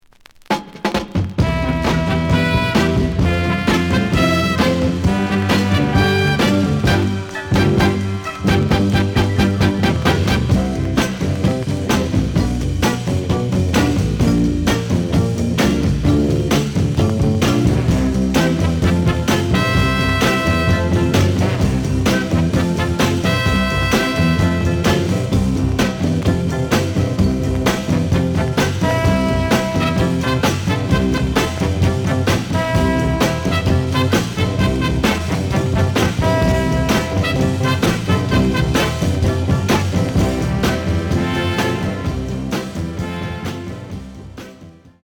(Instrumental)
The audio sample is recorded from the actual item.
●Format: 7 inch
●Genre: Soul, 60's Soul